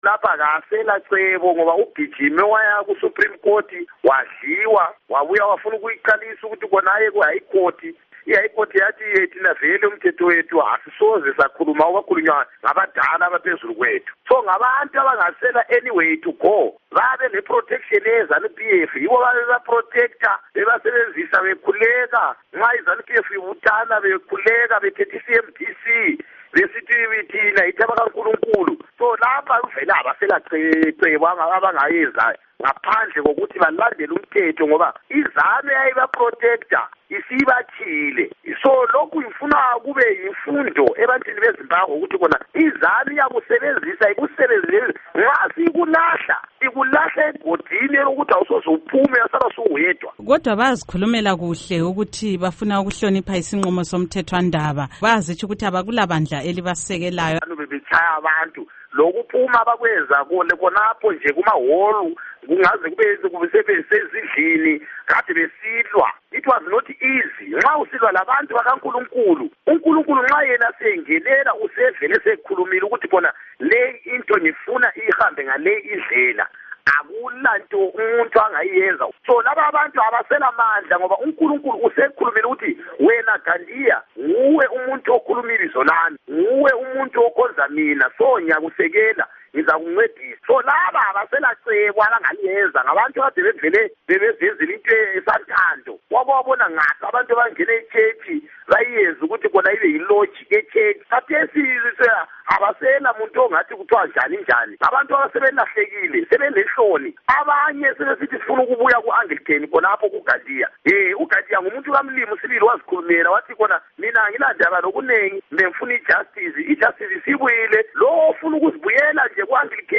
Ingxoxo LoKhansila Peter Moyo